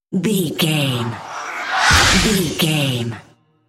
Airy whoosh hit horror squeal
Sound Effects
In-crescendo
Atonal
scary
ominous
haunting
eerie
woosh to hit